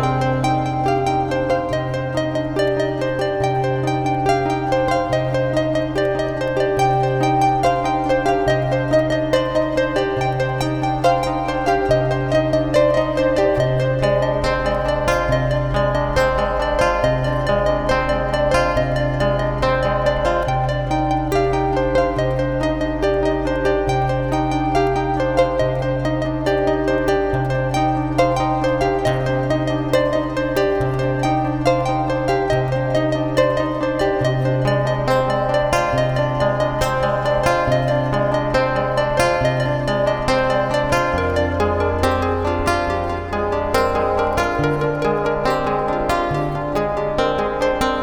Today I am launching Ad Infinitum: a series of short loops which you can download and play (or even jam over) for free.